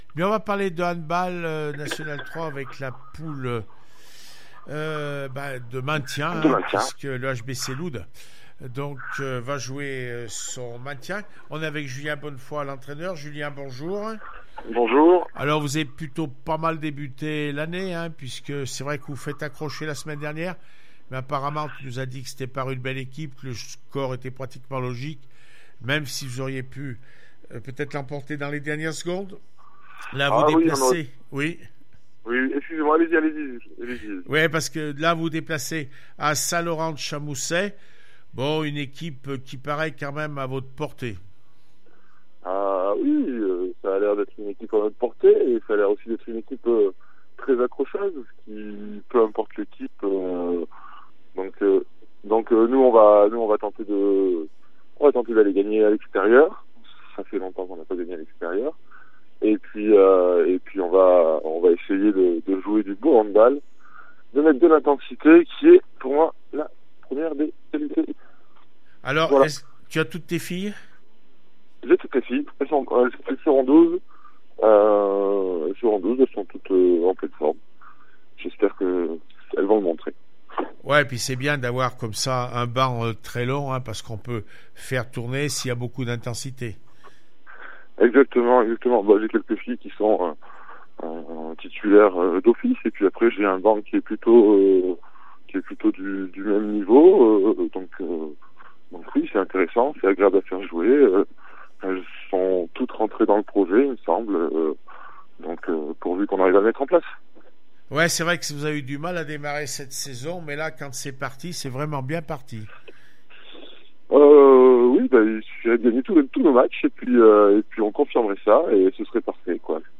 25 janvier 2020   1 - Sport, 1 - Vos interviews, 2 - Infos en Bref   No comments